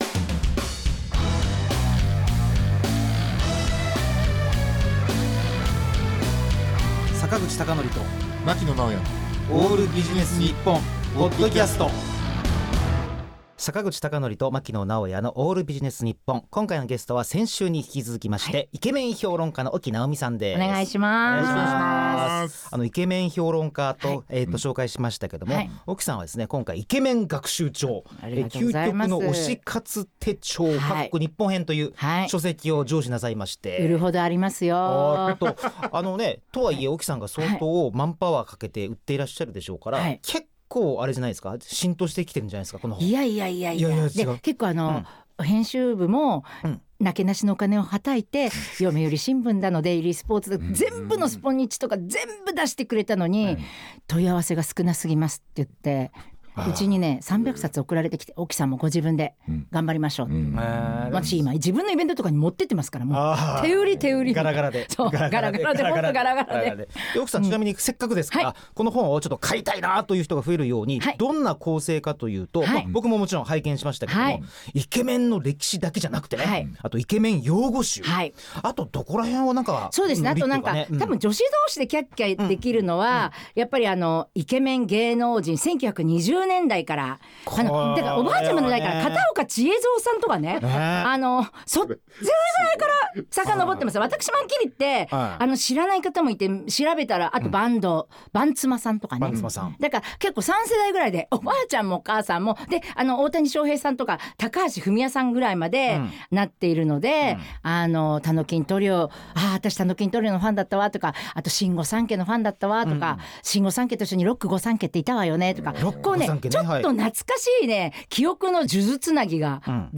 2024年11月25日（月）O.Aより（FM世田谷83.4MHz）